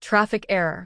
audio_traffic_error.wav